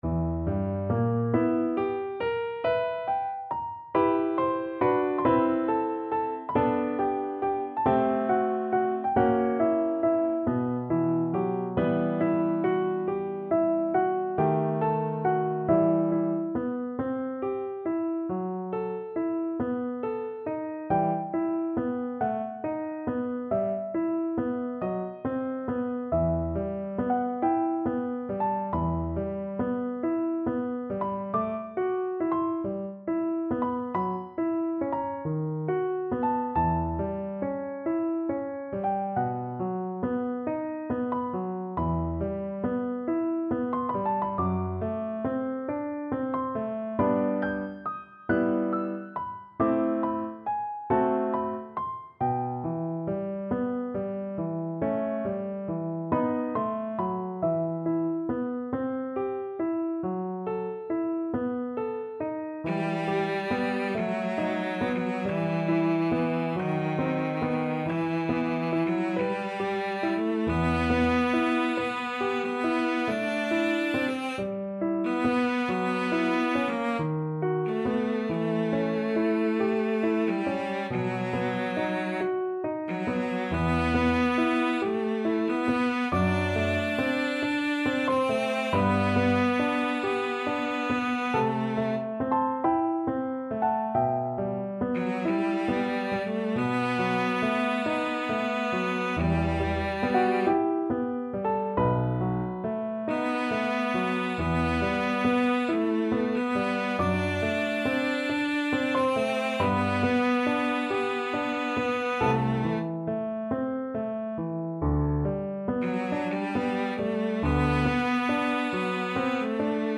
Cello version
4/4 (View more 4/4 Music)
Slow =c.46
Classical (View more Classical Cello Music)